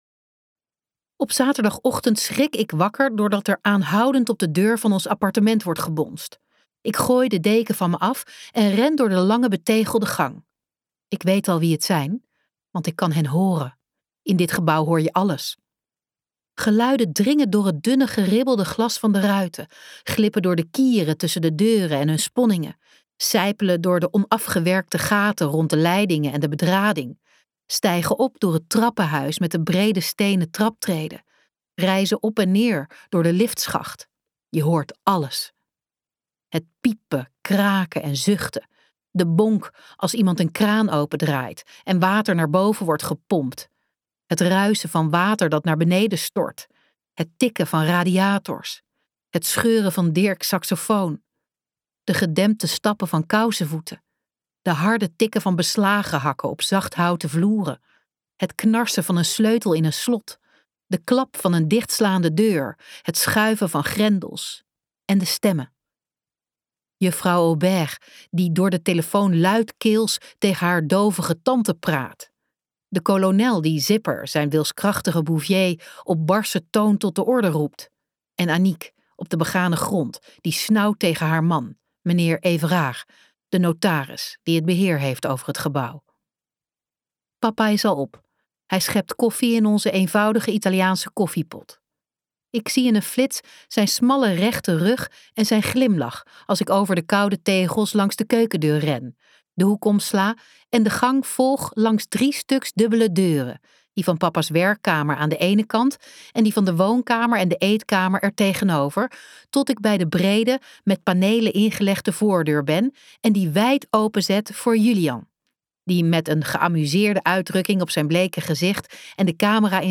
Ambo|Anthos uitgevers - Place brugmann 33 luisterboek